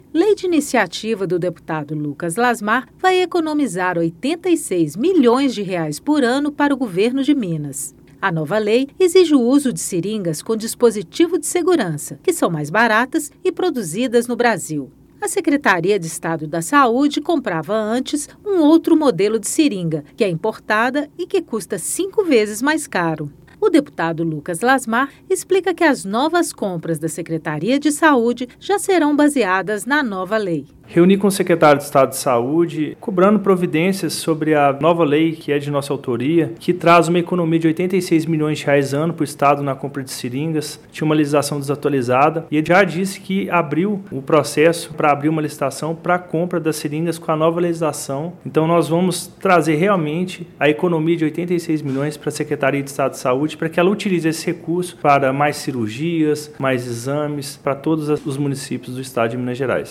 Boletim de Rádio Lucas Lasmar